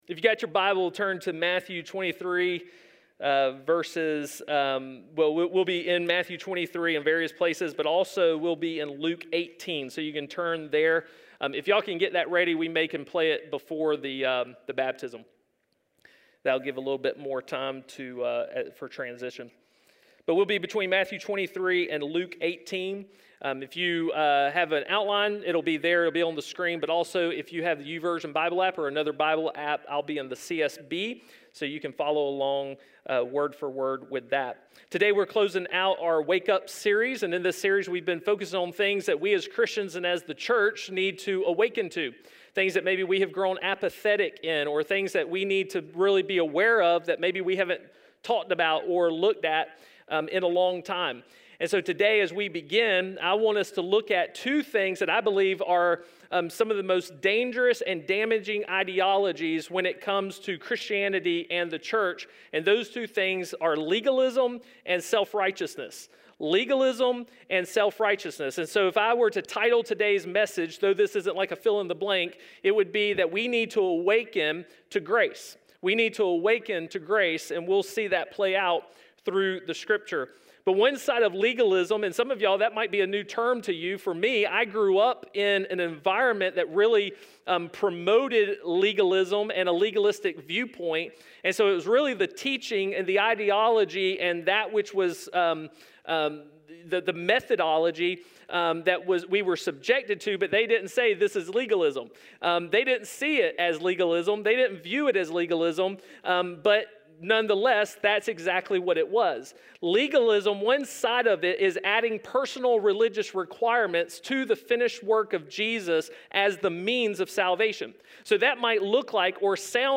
A message from the series "RESET." There are times when we lose focus, or forget the purpose for why we do what we do; it’s in those moments that we need to stop, hit the reset button, and recalibrate our focus.